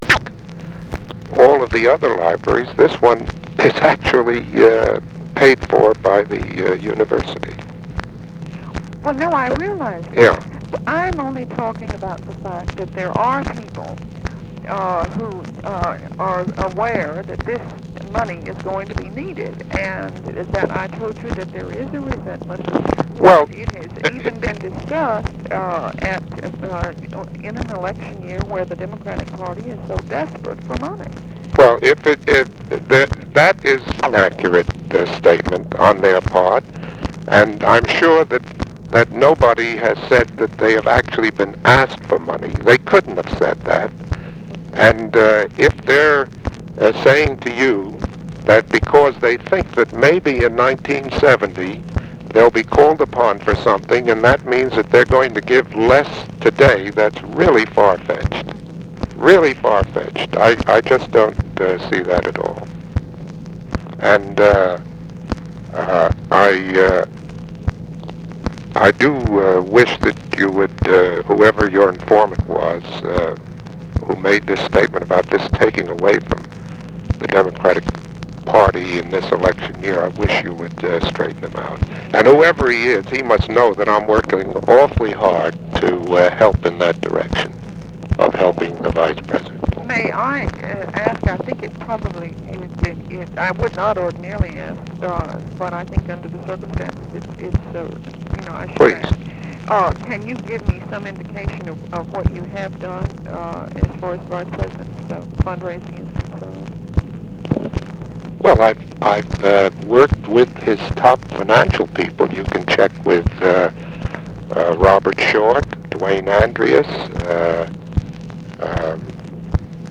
Conversation with MAXINE CHESHIRE
Secret White House Tapes